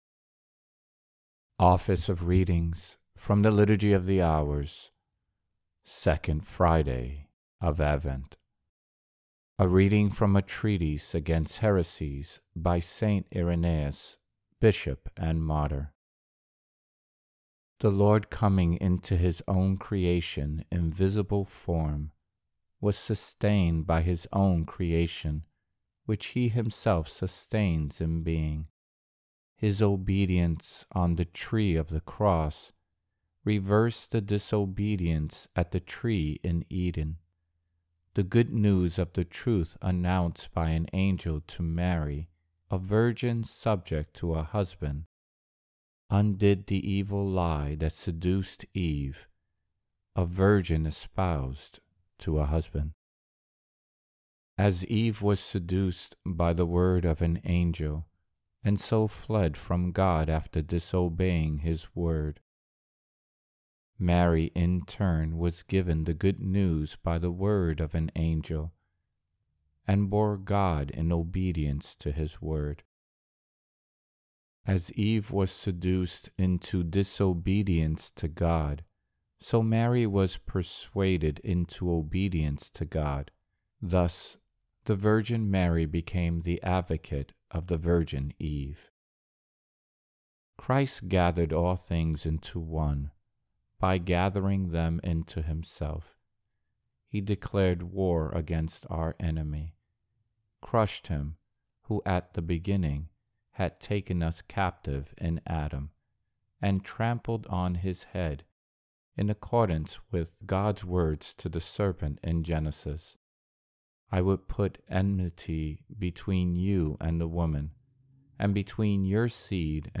Office of Readings – 2nd Friday of Advent